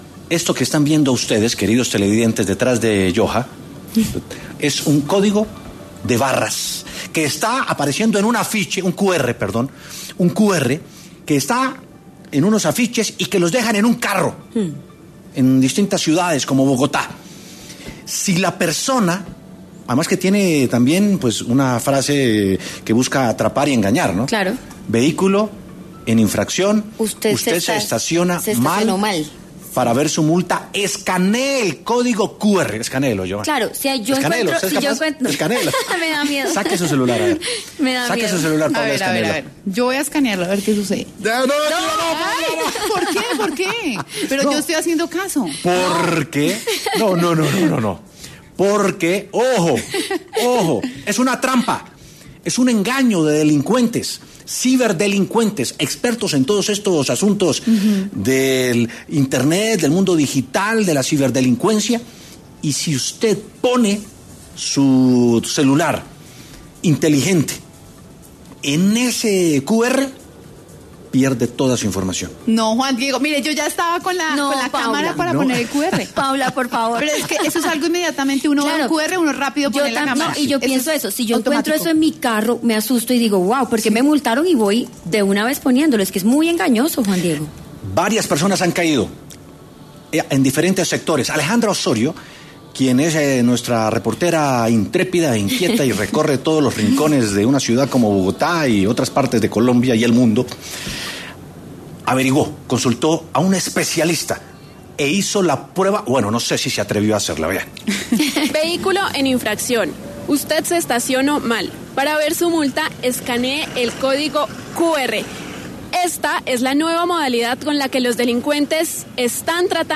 Este medio salió a las calles para consultarle a los bogotanos si tenían conocimiento de esta modalidad de robo.
W Sin Carrera consultó con un experto en ciberseguridad que explicó cómo hacen estos delincuentes para robarlo.